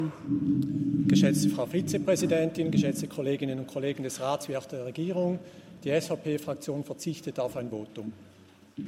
21.2.2024Wortmeldung
Session des Kantonsrates vom 19. bis 21. Februar 2024, Frühjahrssession